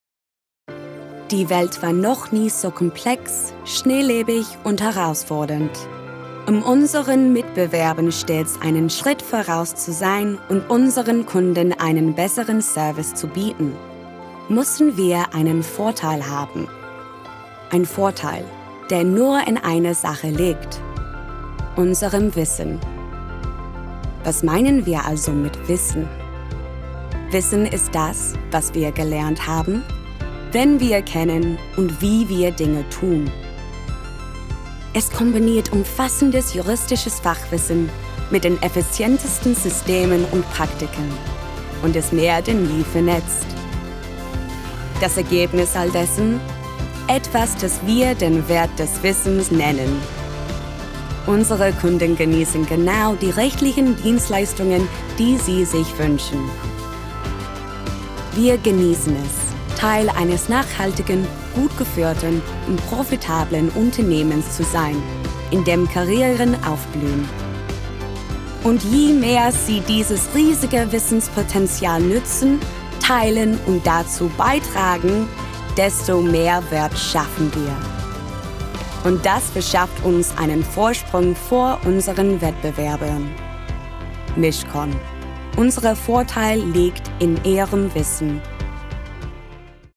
Young, Fresh with Warm Husky Tones
Commercial, Bright, Upbeat, Conversational